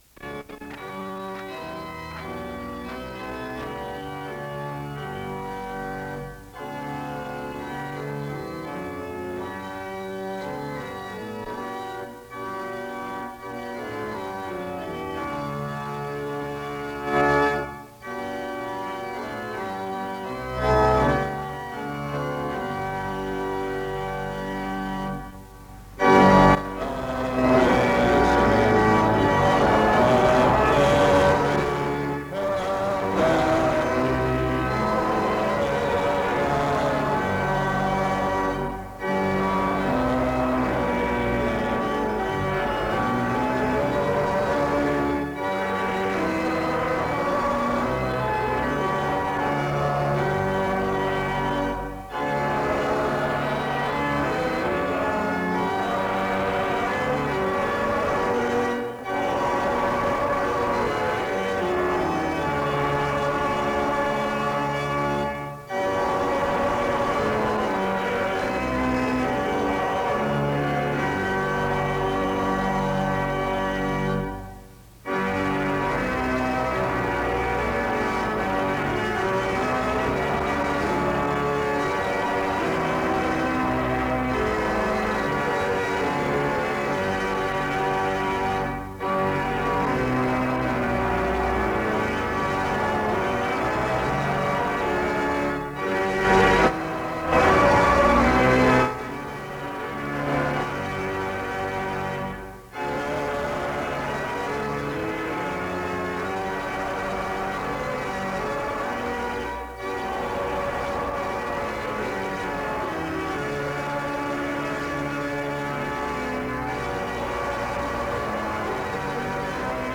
The service begins with music from 0:00-3:05. An introduction is given from 3:18-3:40. A passage of scripture is read from 3:44-6:46. A prayer is offered from 6:49-7:45.
In Collection: SEBTS Chapel and Special Event Recordings SEBTS Chapel and Special Event Recordings